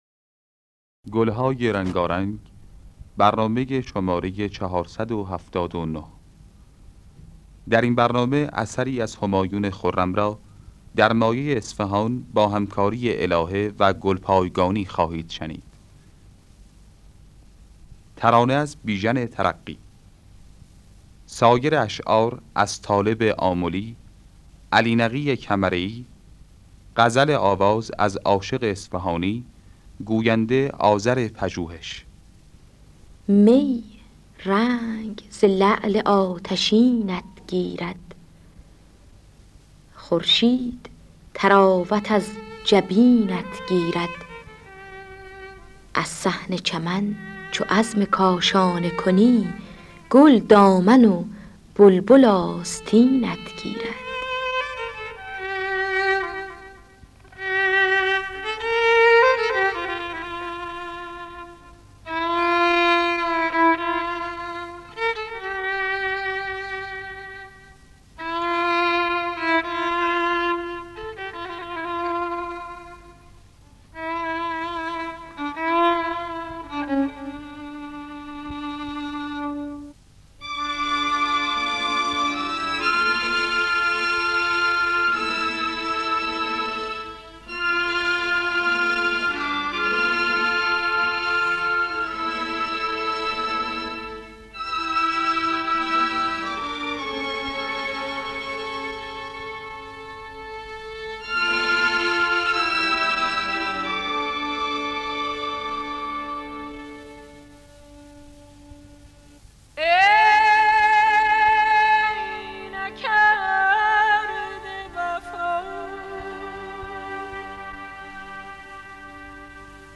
در دستگاه بیات اصفهان